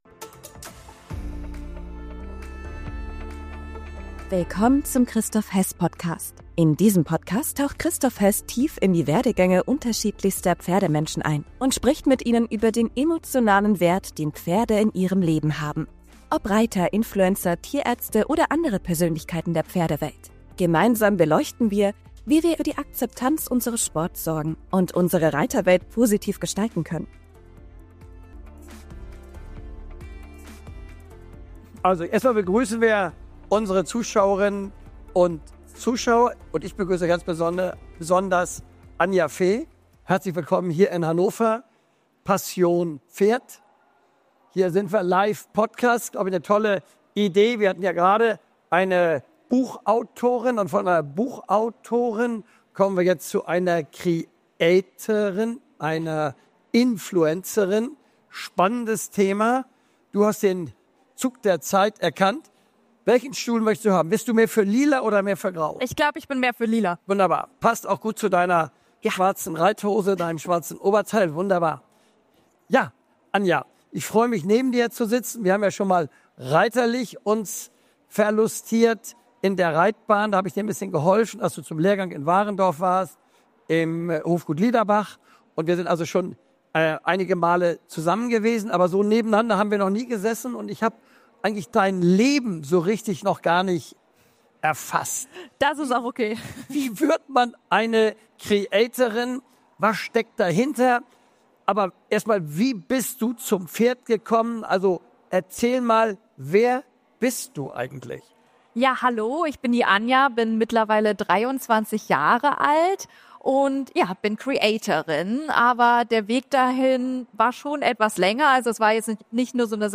Diese Folge wird präsentiert von Signum Sattelservice Live von der Passion Pferd in Hannover.